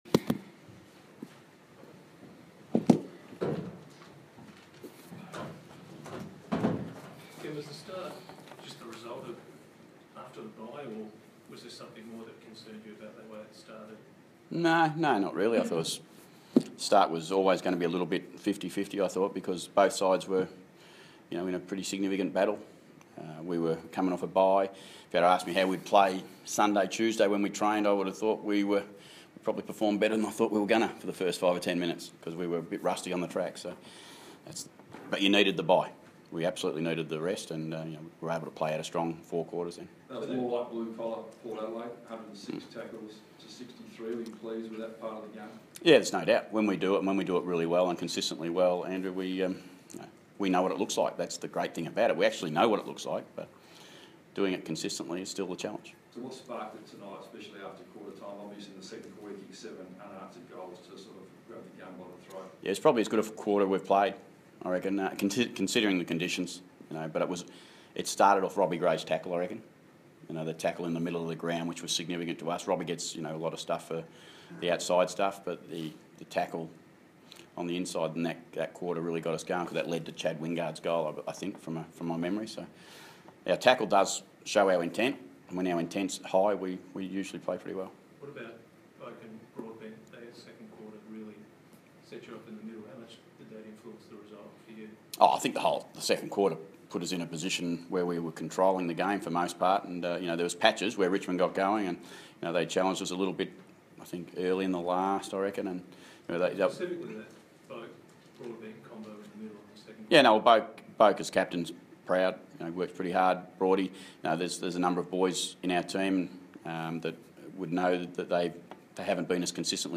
Ken Hinkley Post-match Press Conference - Friday, 1 July, 2016